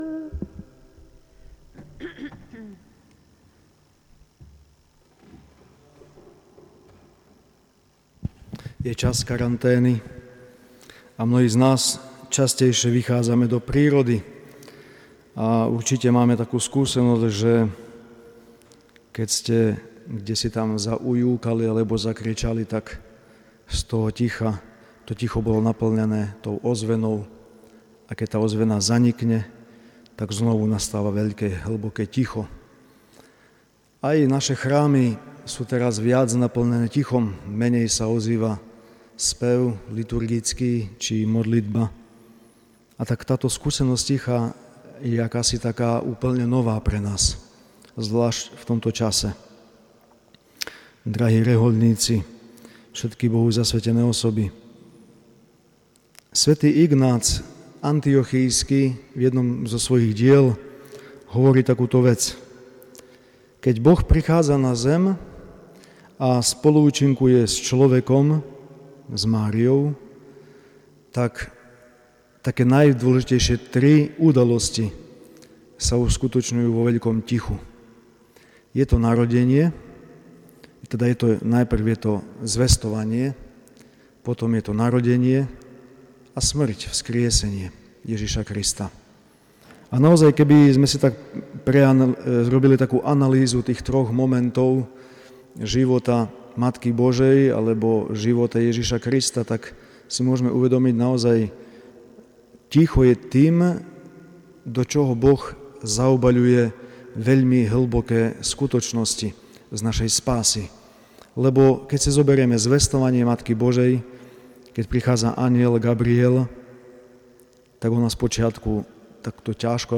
Februárová fatimská sobota sa v bazilika niesla v duchu dňa zasväteného života.